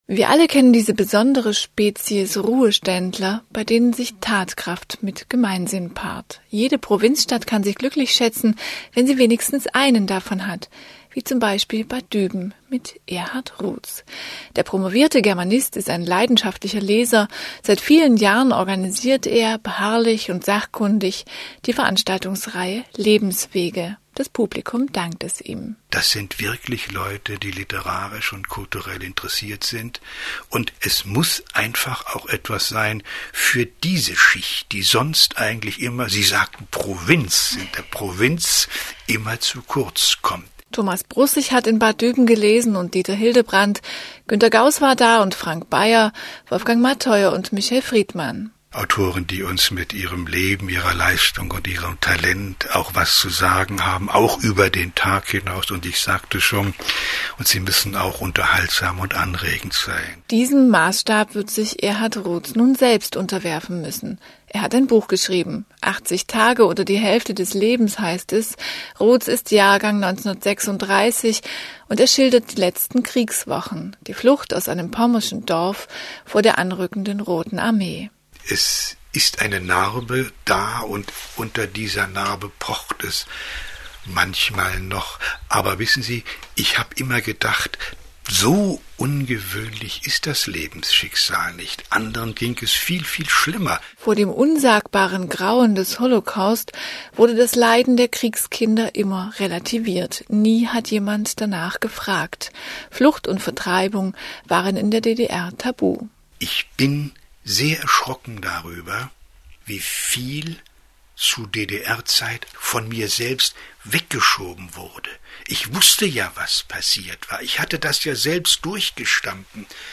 Radiogespr�che